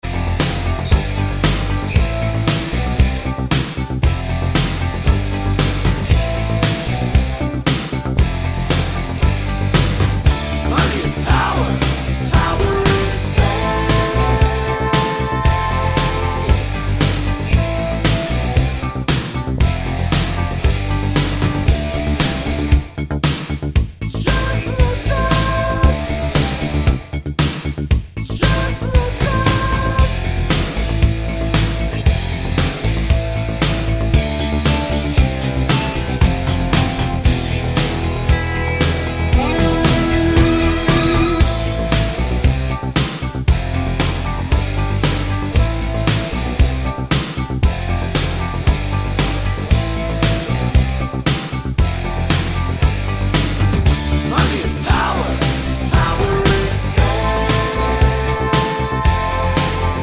For Solo Singer Rock 4:53 Buy £1.50